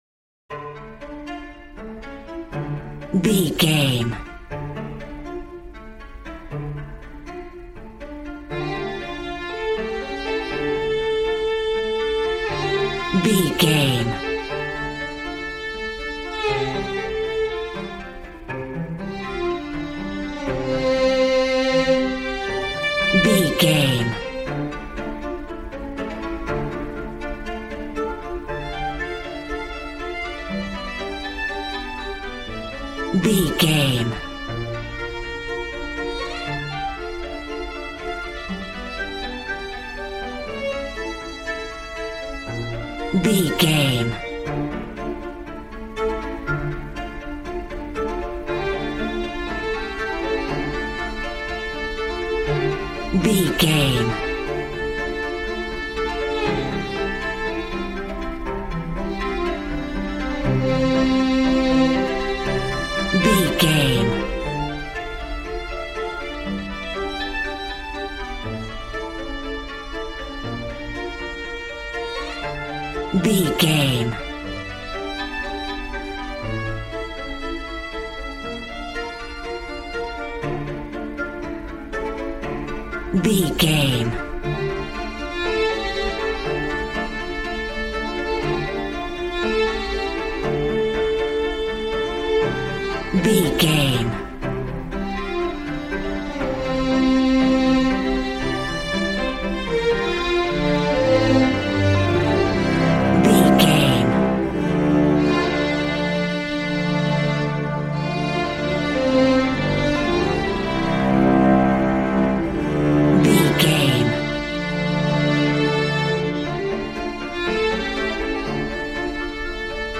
A warm and stunning piece of playful classical music.
Regal and romantic, a classy piece of classical music.
Aeolian/Minor
Fast
regal
piano
violin
strings